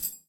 Sfx Getcoin Sound Effect
sfx-getcoin-6.mp3